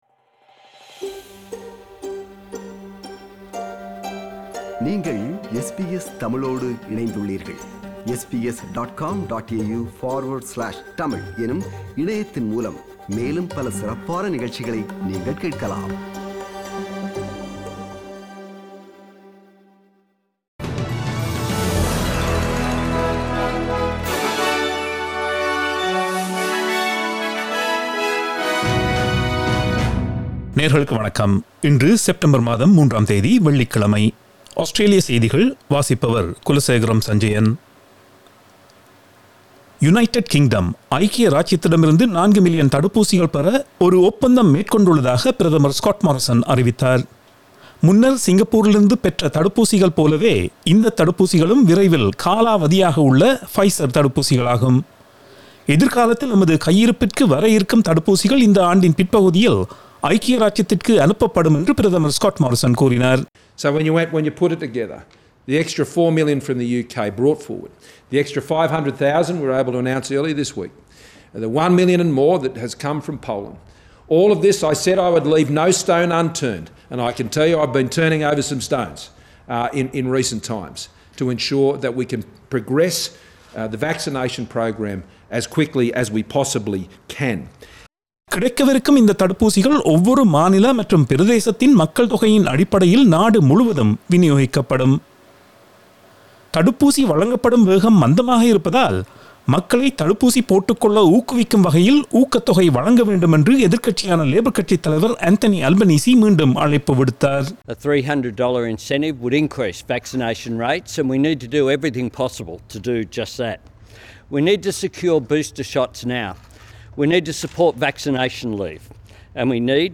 Australian news bulletin for Friday 03 September 2021.